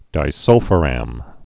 (dī-sŭlfə-răm)